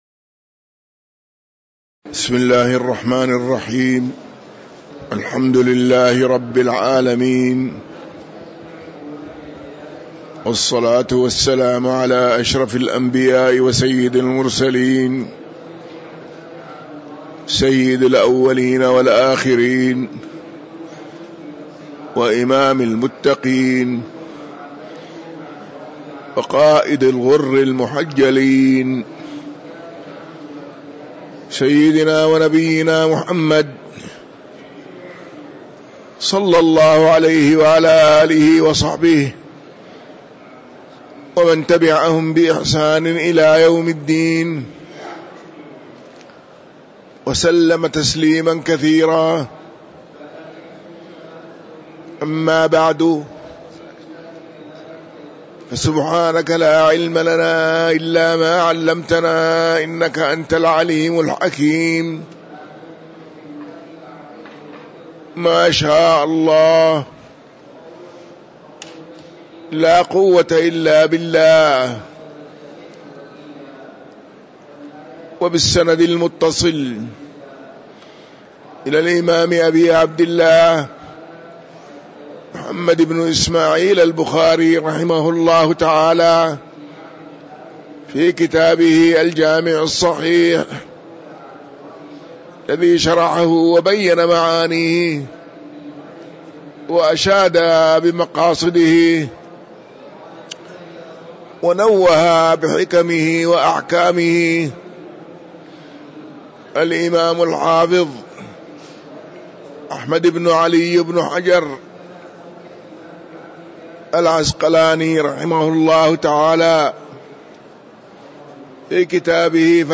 تاريخ النشر ١٦ رجب ١٤٤٥ هـ المكان: المسجد النبوي الشيخ